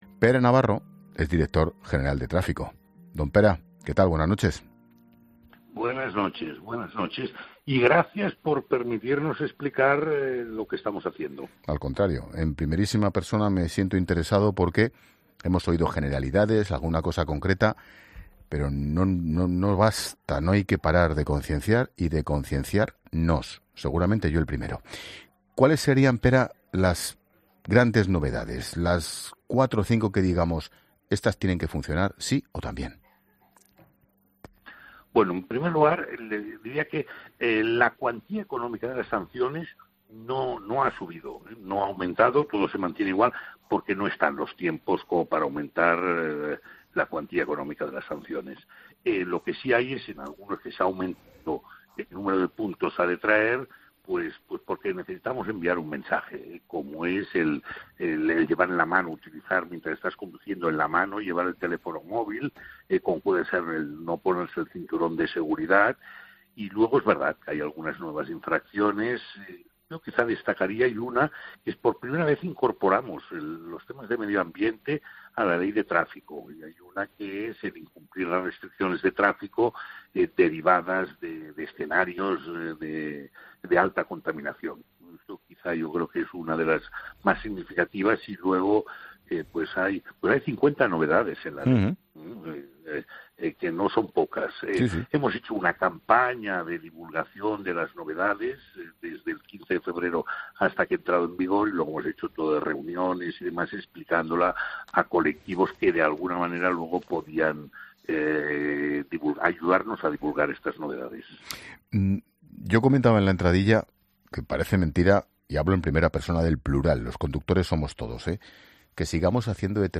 Con más de 50 modificaciones, Pere Navarro, el director general de tráfico, es el invitado idóneo para explicar en 'La Linterna' de COPE cuáles son las principales novedades y cómo va a cambiar nuestro día a día.
Pere Navarro explica qué hay detrás del nuevo límite de velocidad Ángel Expósito ha querido preguntar a Navarro por una de las medidas más polémicas en la nueva Ley de Tráfico.